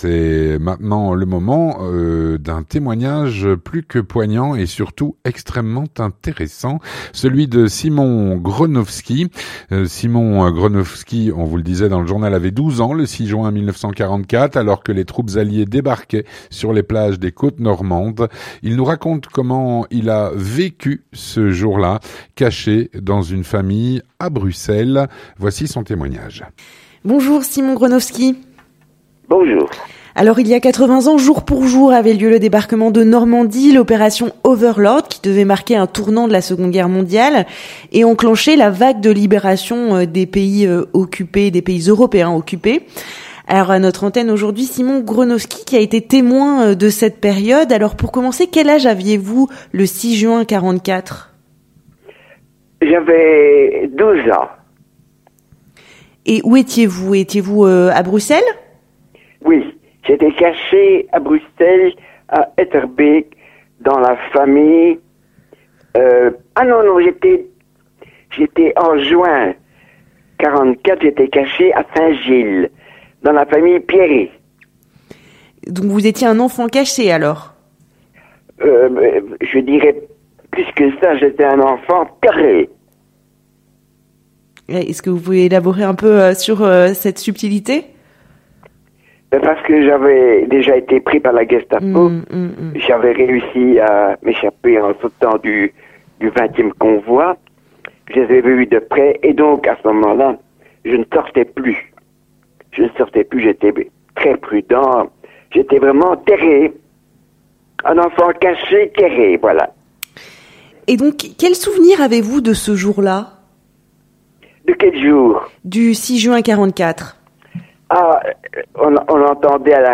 Témoignage - Il avait 12 ans le 6 Juin 1944.